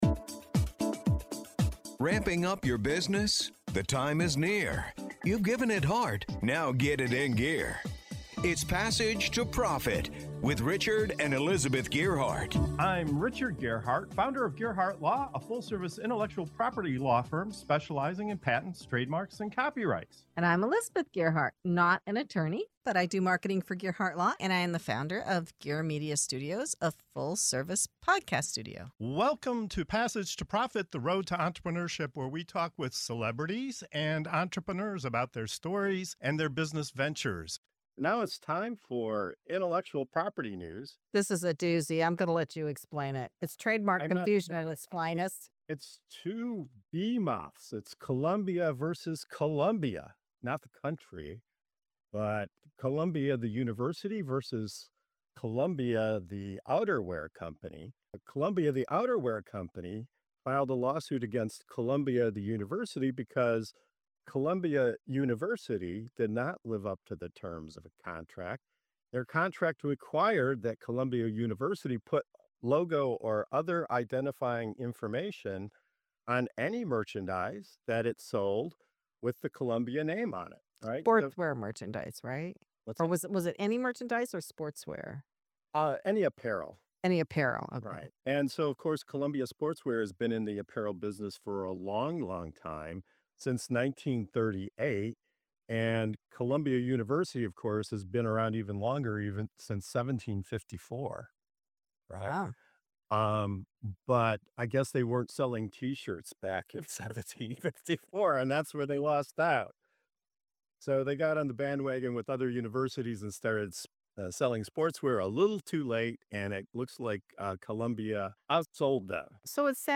What happens when a global outdoor brand and an Ivy League institution both stake their claim to the same name on apparel? From contracts and logos to brand confusion and regional recognition, our panel unpacks the controversy, explores both sides of the argument, and reveals what this unusual dispute teaches us about intellectual property, branding, and business strategy.